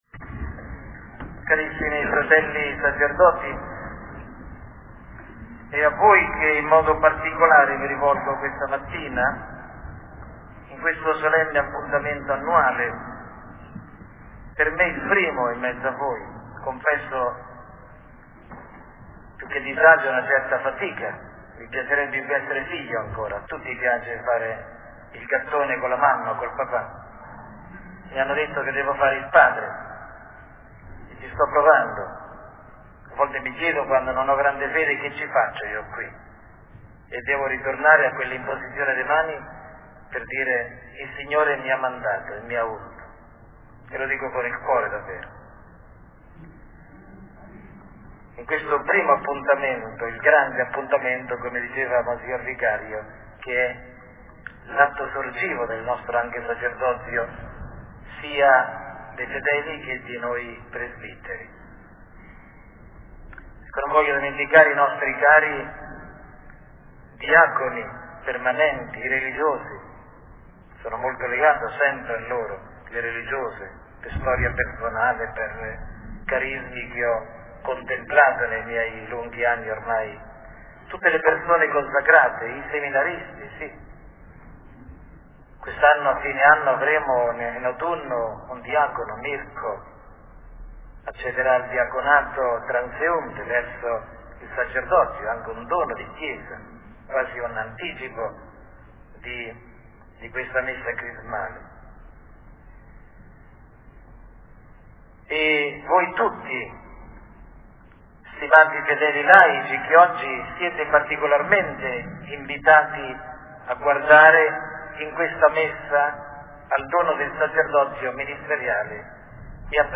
E’ disponibile il testo completo e l’audio dell’omelia che Mons. Armando Trasarti ha tenuto nel suo primo Giovedì Santo della Messa Crismale nella Cattedrale di Fano. Un ricordo particolare ai sacerdoti anziani, malati, provati e ai nostri missionari fidei donum in Kenya che contatto telefonicamente per gli auguri di Pasqua.